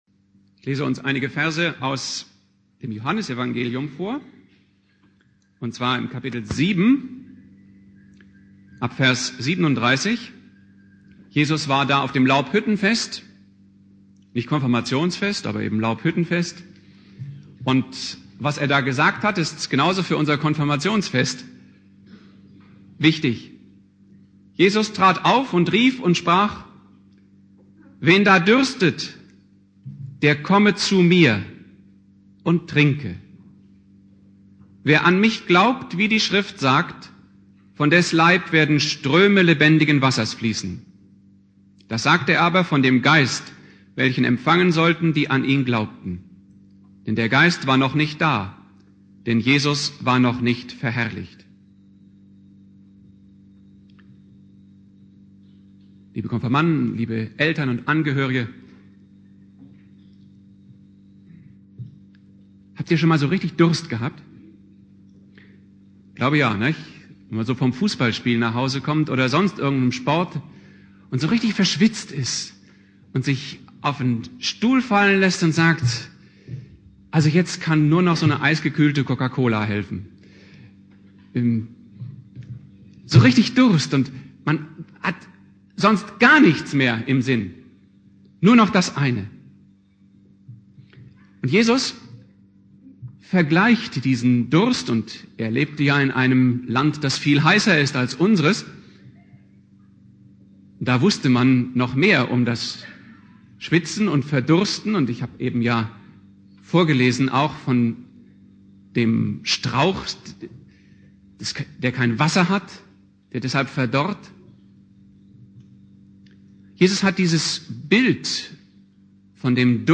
Predigt
"Durst" (Konfirmation Hausen) Bibeltext